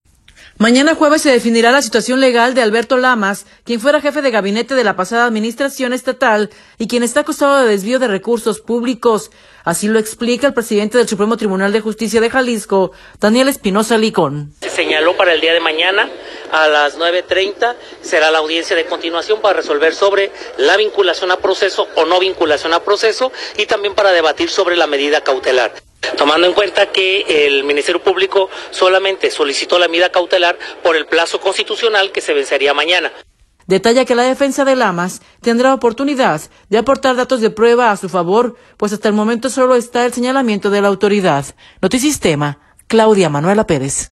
Mañana jueves se definirá la situación legal de Alberto Lamas, quien fuera jefe de gabinete de la pasada administración estatal y quien está acusado de desvío de recursos públicos, así lo explica el presidente del Supremo Tribunal de Justicia de Jalisco, Daniel Espinosa Licón.